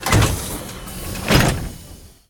ElevatorClose2.ogg